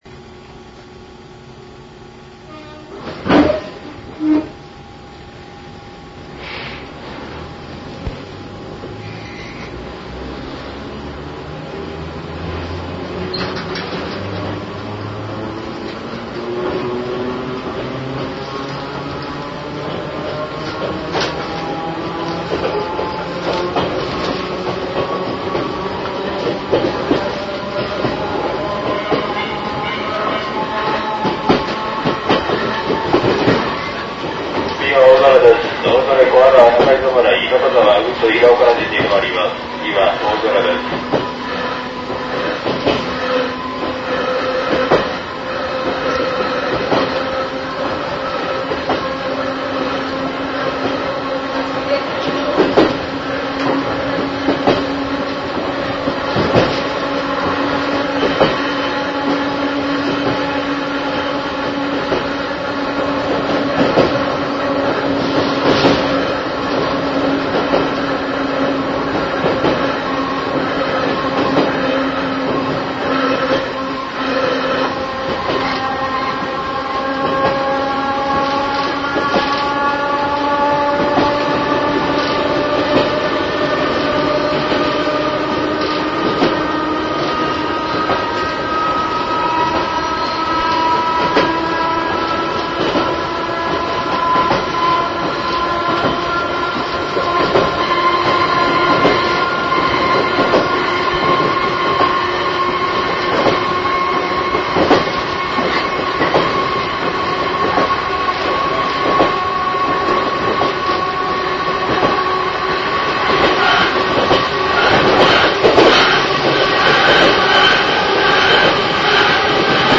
元テープは古く、また録音技術も悪いため、音質は悪いかも知れません。
８３，２　水窪・大嵐間　水窪発車後、大原トンネルに向ってパラに進段し加速